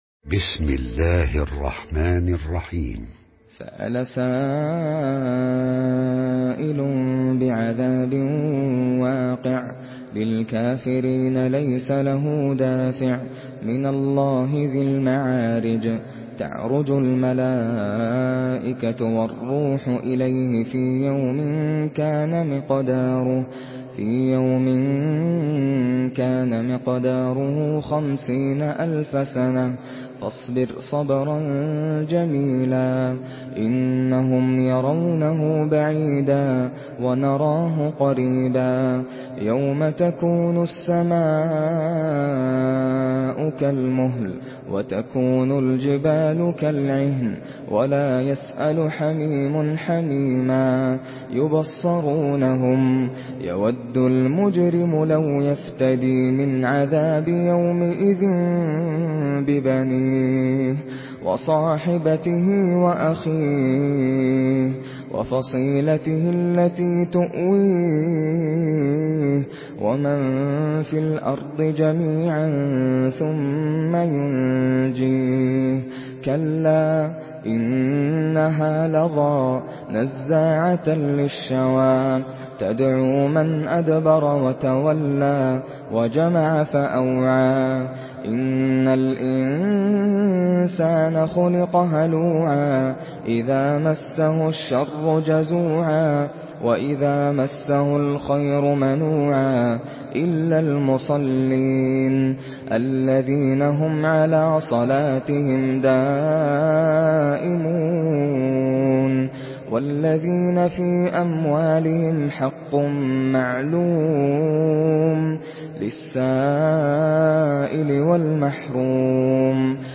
سورة المعارج MP3 بصوت ناصر القطامي برواية حفص
مرتل